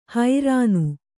♪ hairānu